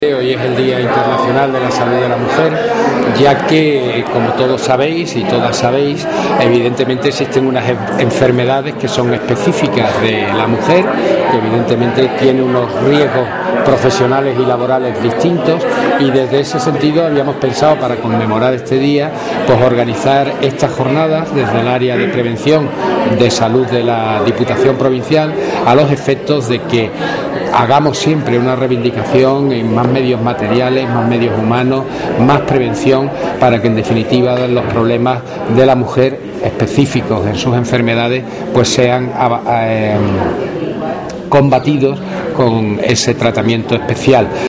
Luis Ángel Fernández , diputado de Función Pública y Recursos Humanos, ha destacado en esta celebración que la labor de prevención de la salud es muy importante en los centros de trabajo.